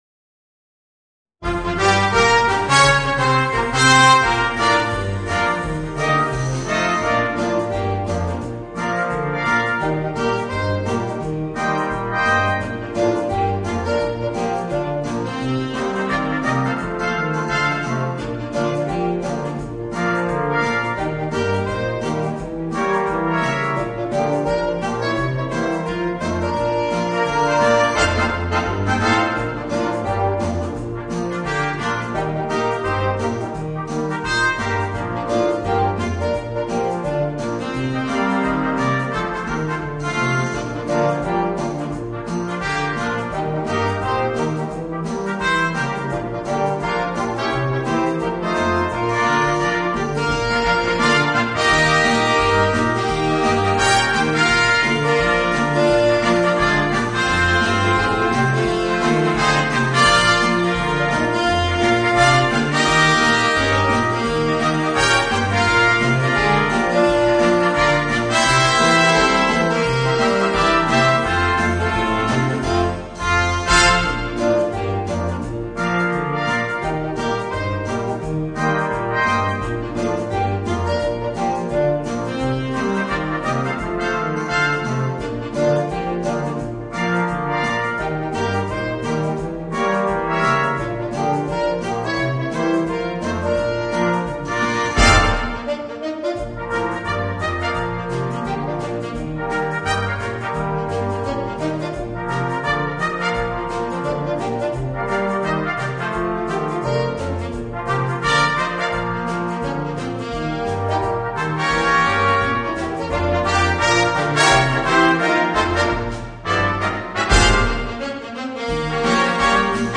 Voicing: Jazz Octet